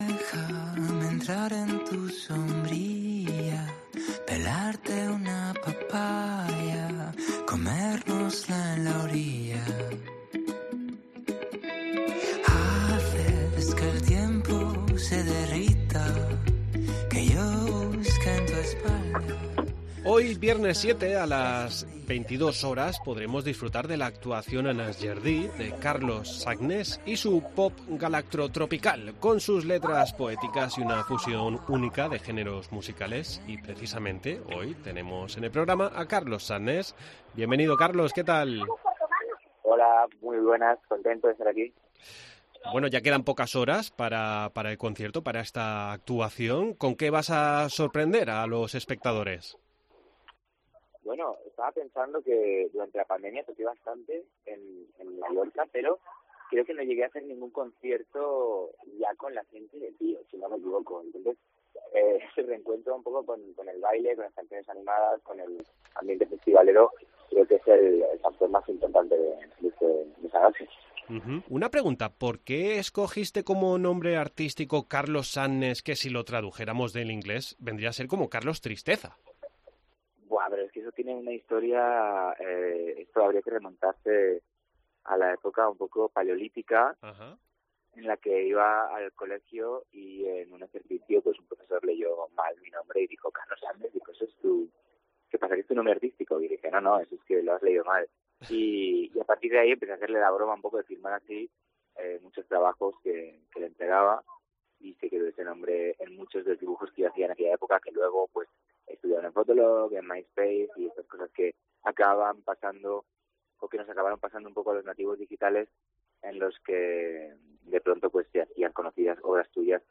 Entrevista con Carlos Sadness por su actuación en Es Jardí el viernes 7 de julio
Hablamos con el cantautor en La Mañana en la Baleares